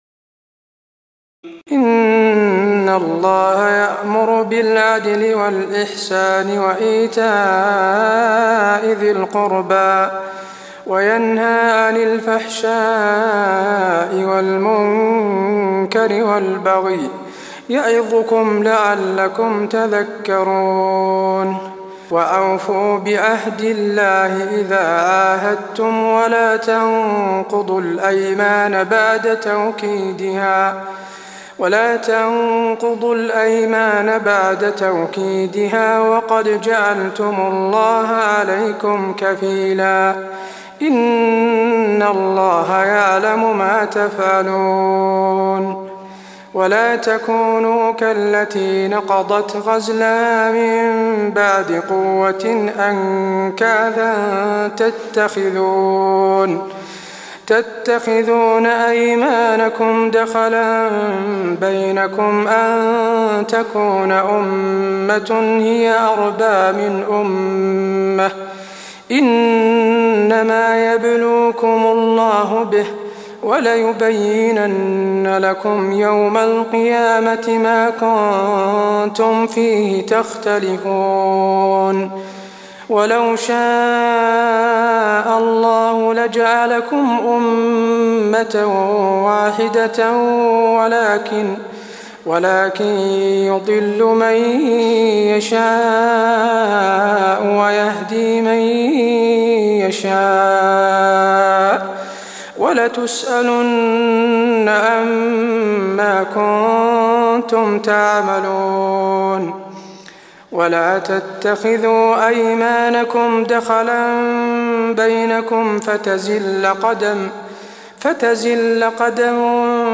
تراويح الليلة الثالثة عشر رمضان 1423هـ من سورة النحل (90-128) Taraweeh 13 st night Ramadan 1423H from Surah An-Nahl > تراويح الحرم النبوي عام 1423 🕌 > التراويح - تلاوات الحرمين